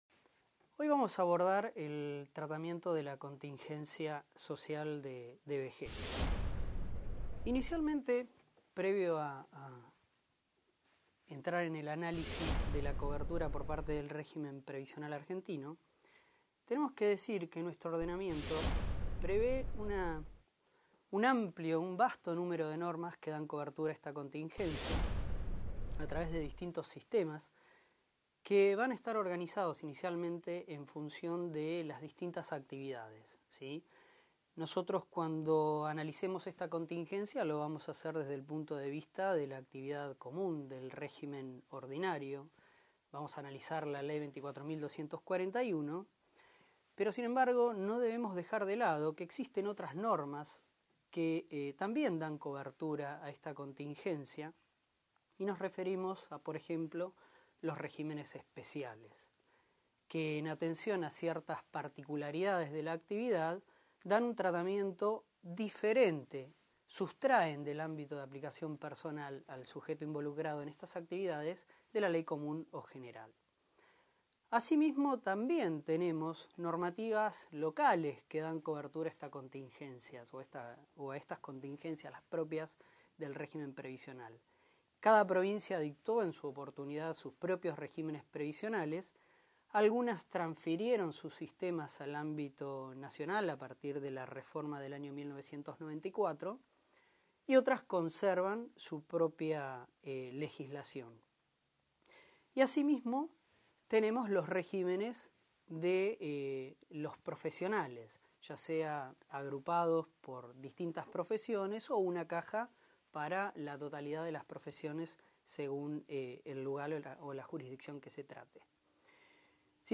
Audio de la clase Descargar clase en formato MP3 MP3 � Anterior - Inicio - Siguiente »